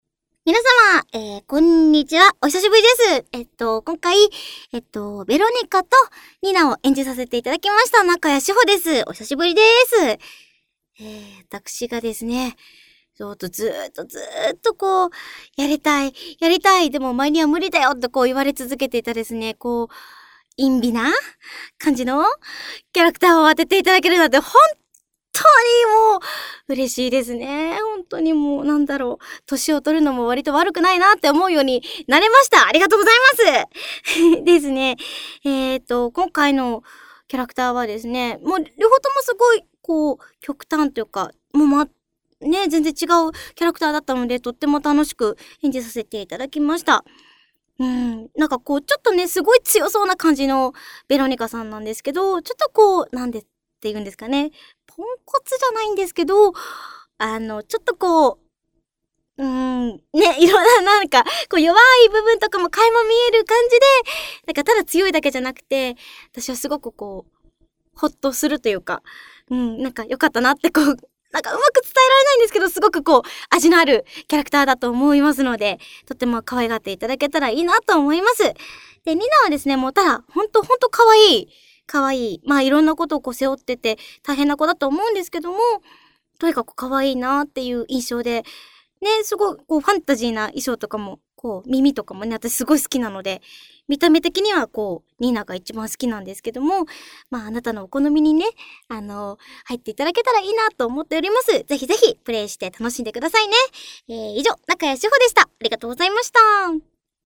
さて、結構量書いた割には内容が薄いですがシステム関係はここらで切り上げ、みなさまお待ちかねの声優さんのコメントです！